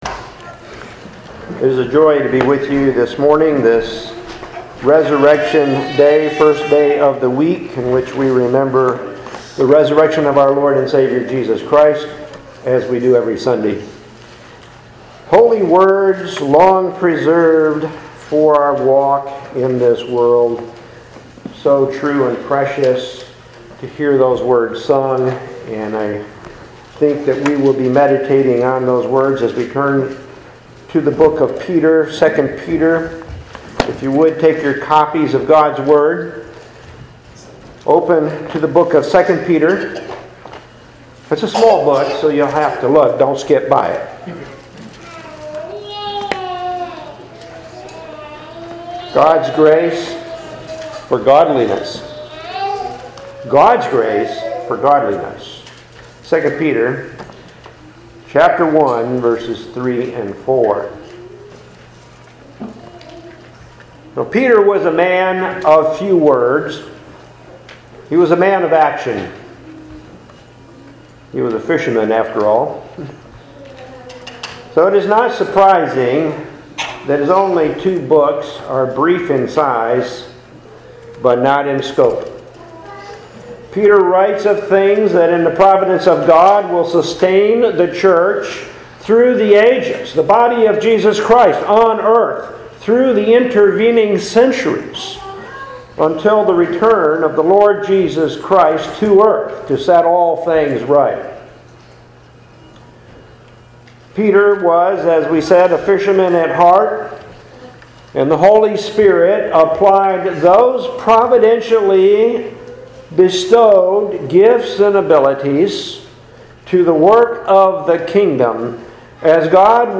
It was preached at Trinity RBC on July 10, 2022.